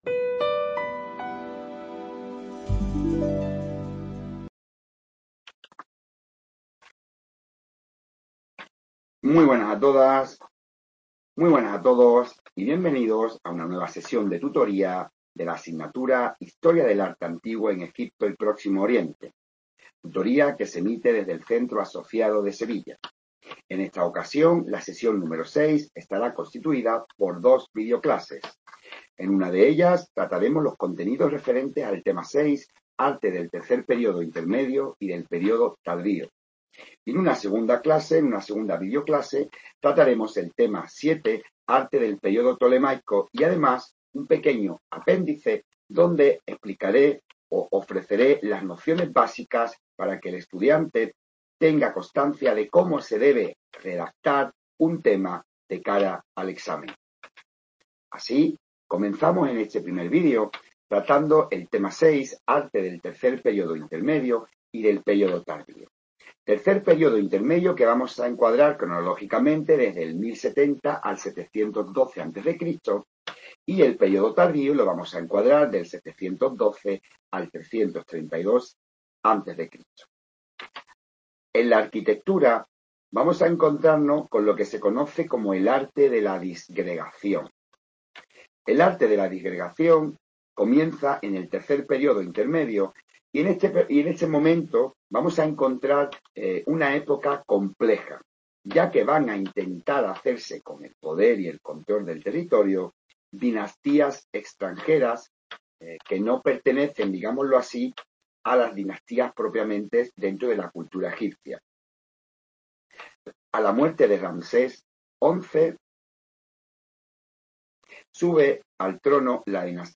Video-clase grabada.